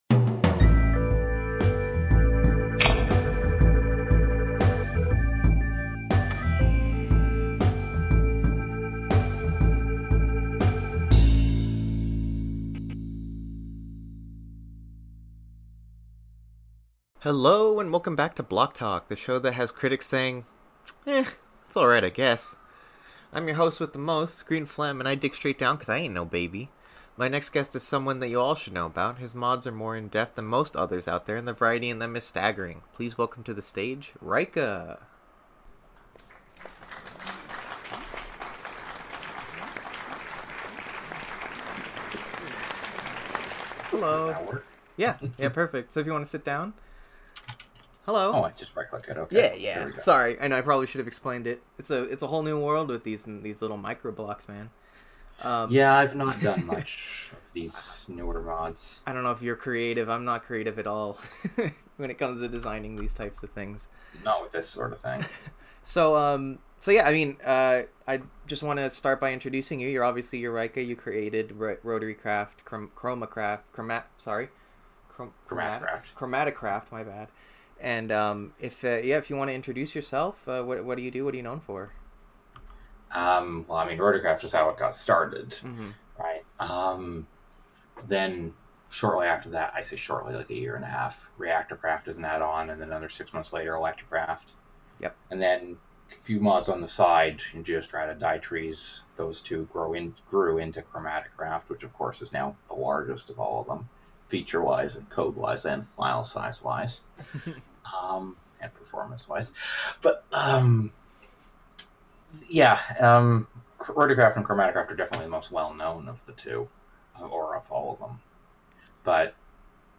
BlockTalk interview